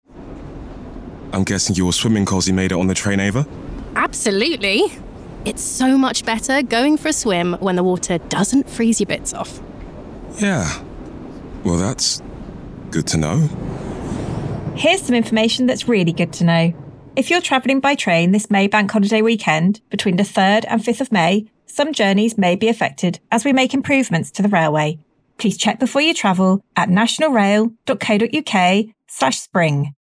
Radio advert